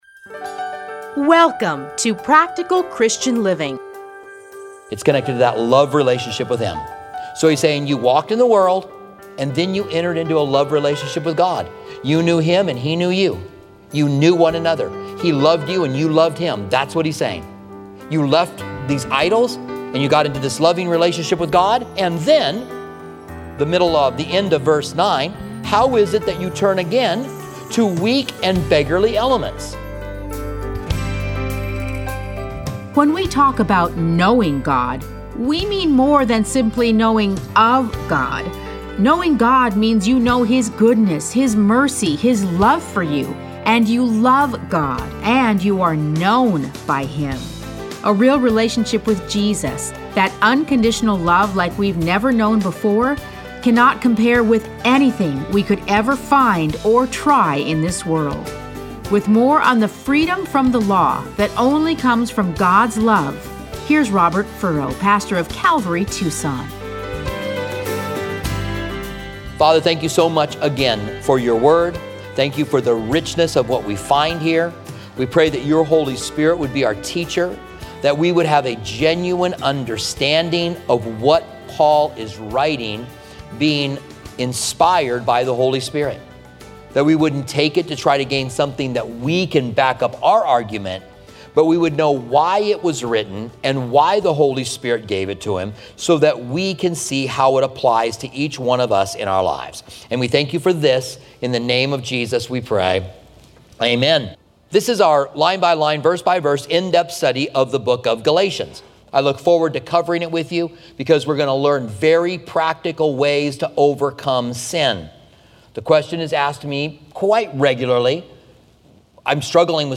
Listen to a teaching from Galatians 4:8-20.